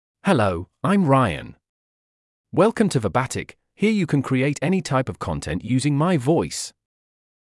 MaleEnglish (United Kingdom)
Ryan is a male AI voice for English (United Kingdom).
Voice sample
Listen to Ryan's male English voice.
Ryan delivers clear pronunciation with authentic United Kingdom English intonation, making your content sound professionally produced.